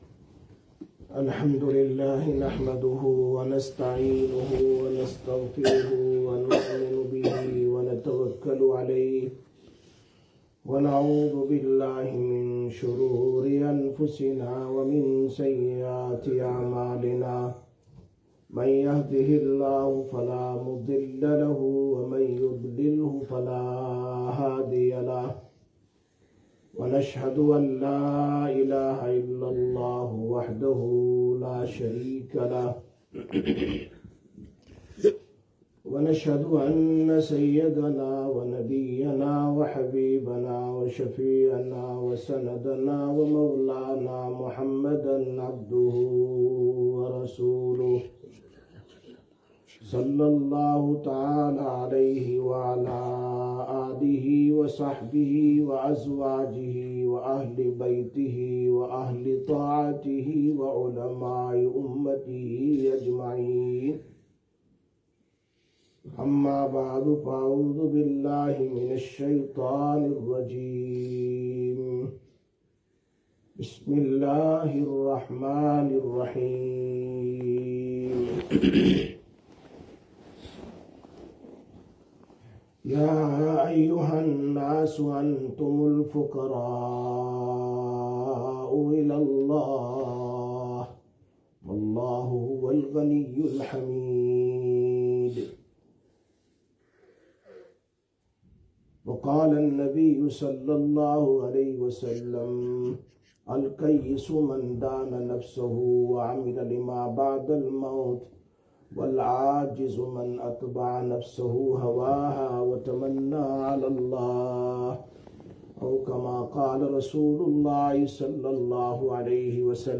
14/02/2025 Jumma Bayan, Masjid Quba